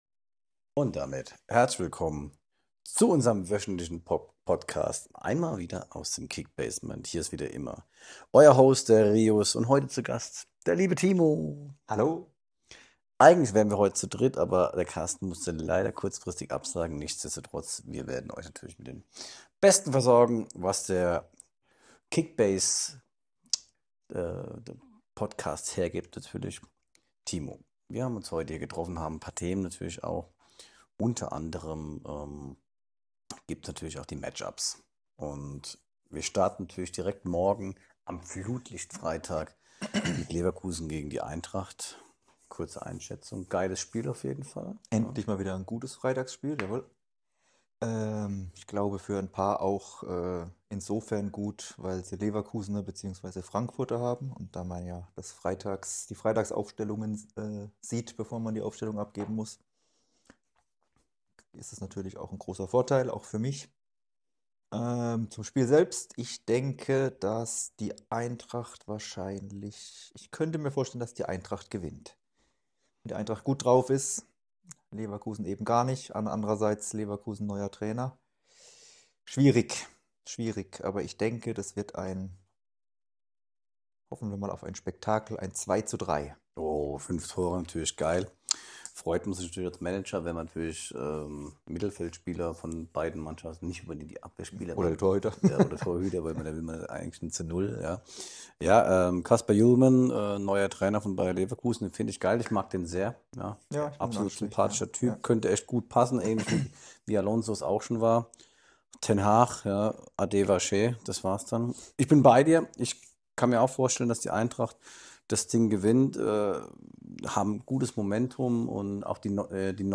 Freut euch über ein angenehmes Gespräch und den ein oder anderen Hot Take vor Spieltag 3.